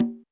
Drums_K4(28).wav